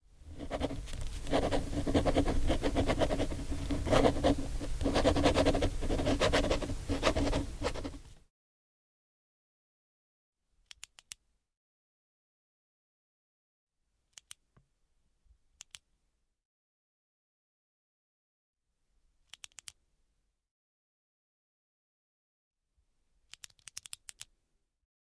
毁灭、冲击、粉碎" 5支毁灭笔
描述：钢笔